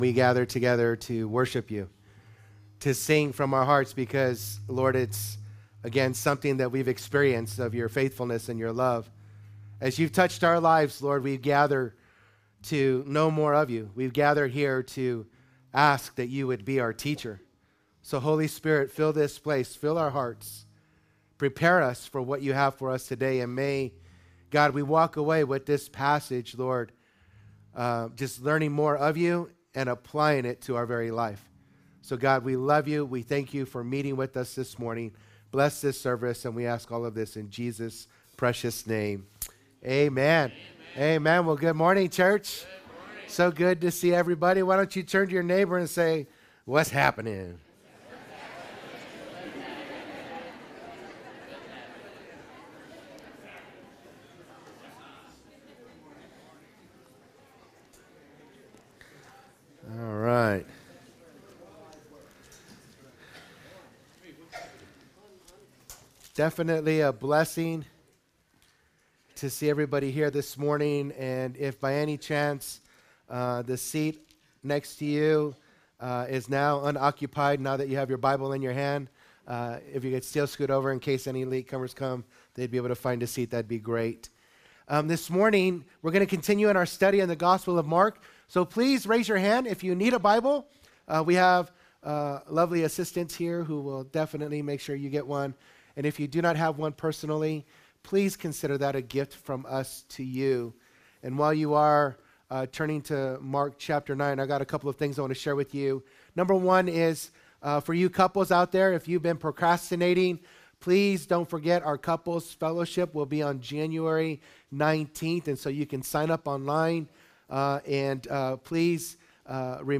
Calvary Chapel Saint George - Sermon Archive